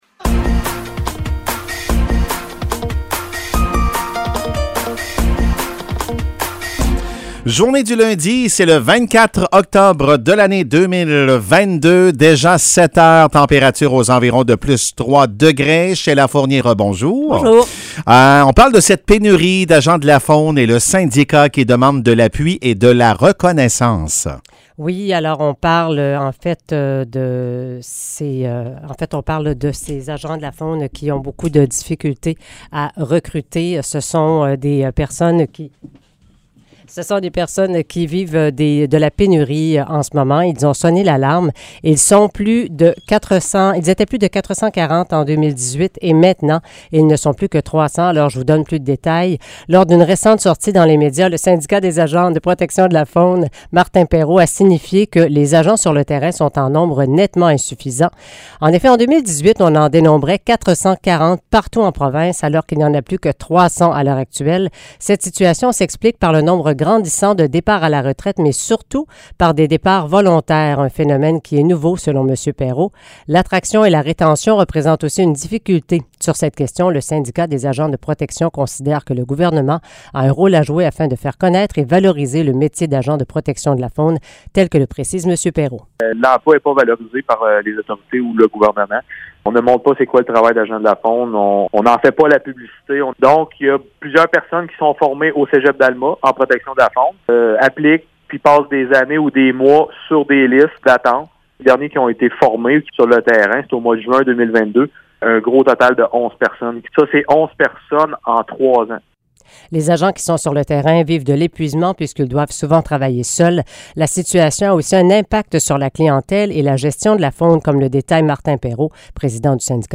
Nouvelles locales - 24 octobre 2022 - 7 h